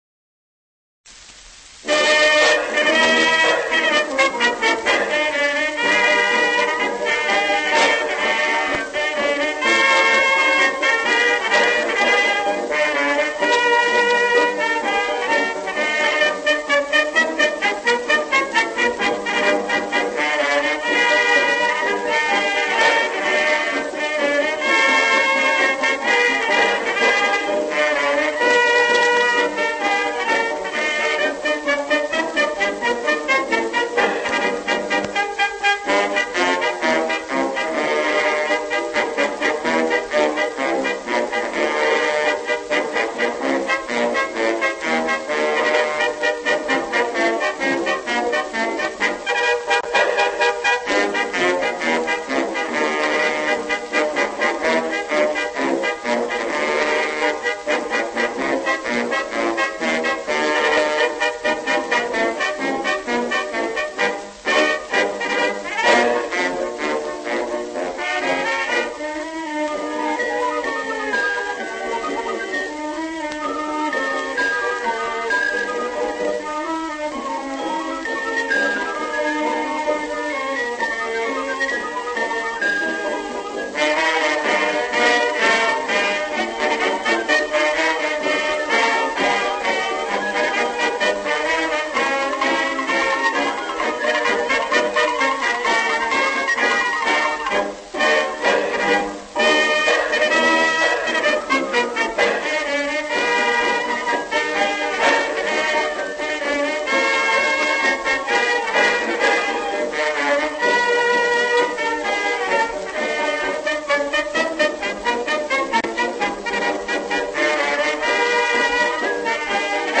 Genre(s): Ethnic music.
Place of Recording: Camden, New Jersey.